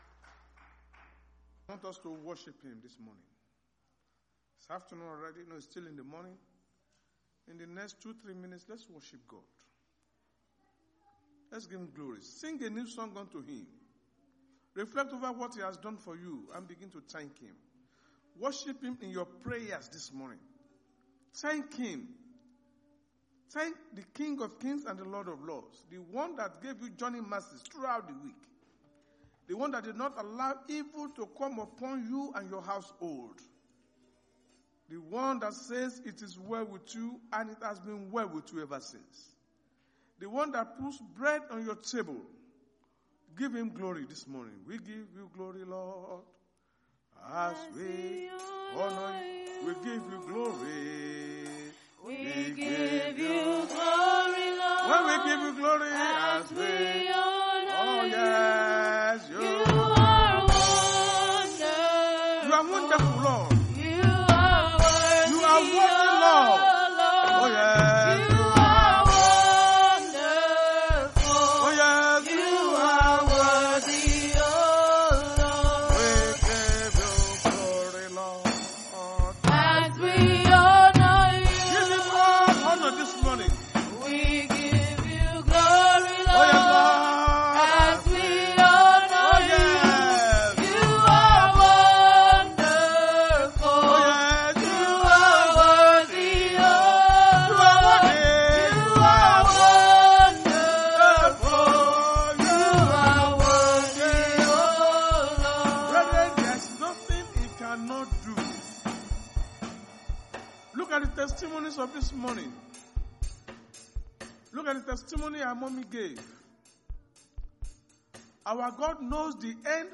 Sunday Sermon: The Lord Make His Countenance Shine Upon Thee
Service Type: Sunday Church Service